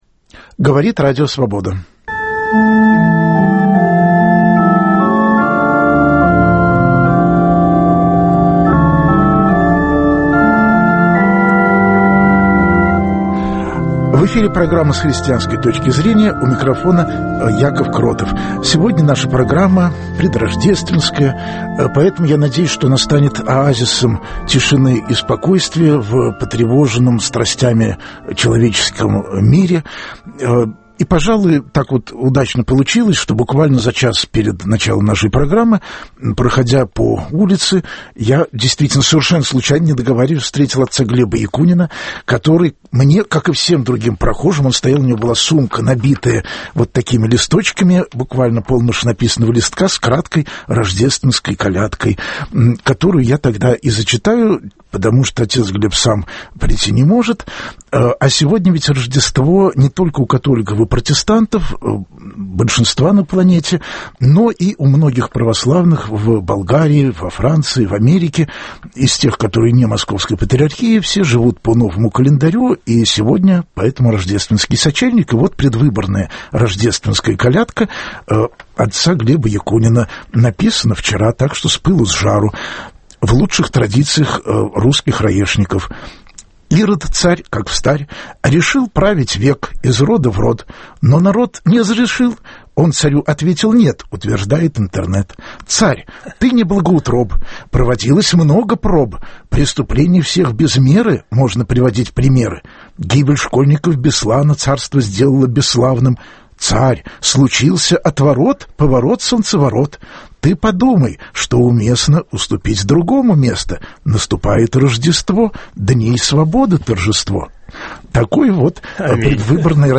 Представители разных христианских конфессий будут говорить об этом в прямом эфире.